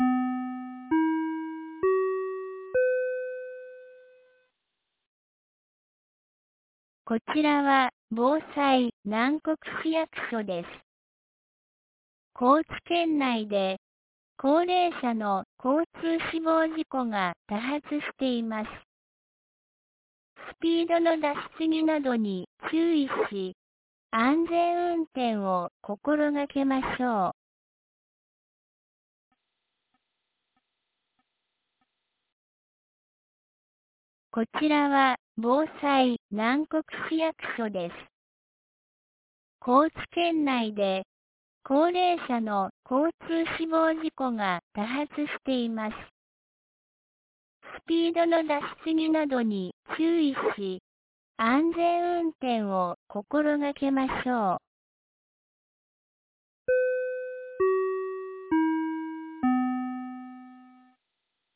2025年08月09日 11時01分に、南国市より放送がありました。